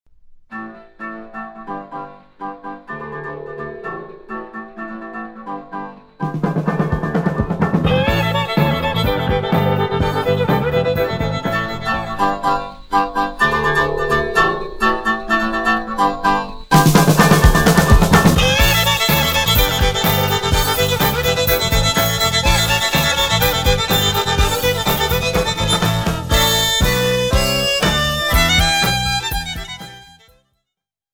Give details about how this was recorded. DEMO - LP Equalization Corrections (Before & After) LPEQUILAZATIONCORRECTIONS(BEFOREANDAFTER).mp3